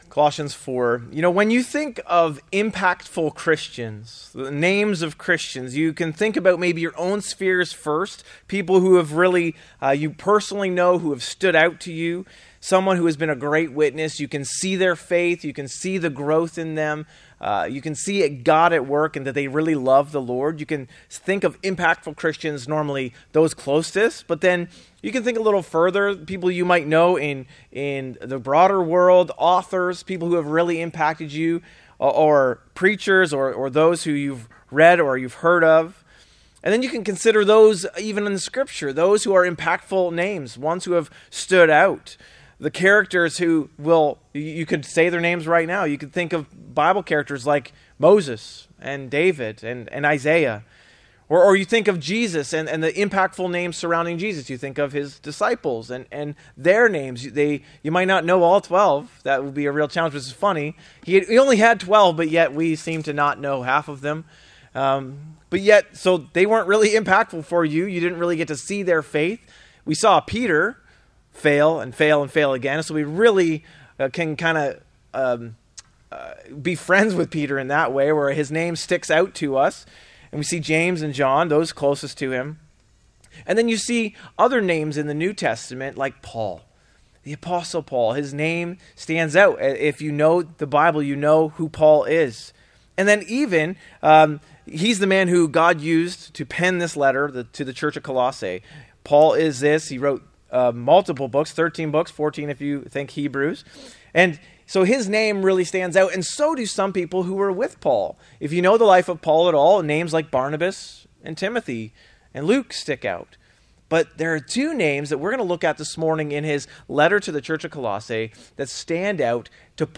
A message from the series "In Christ Alone."